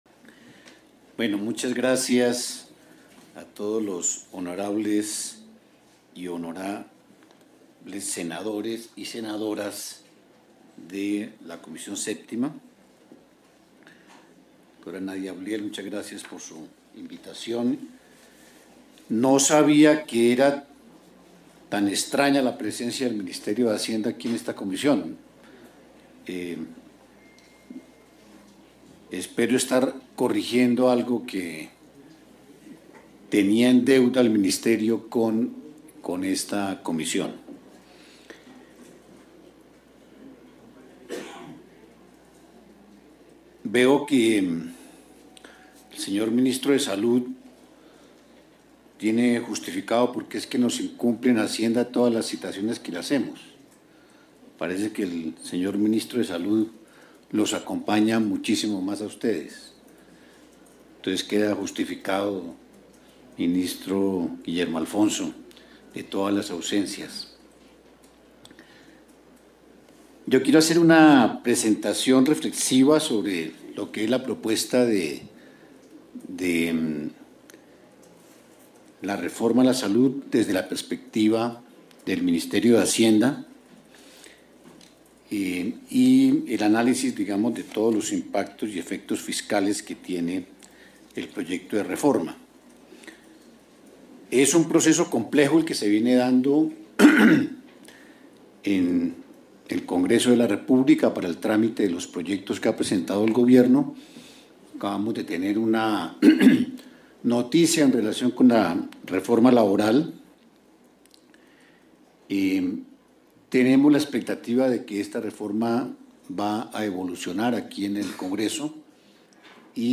Título Intervención del Ministro en Comisión 7 de Senado | Reforma a la Salud | Mayo 27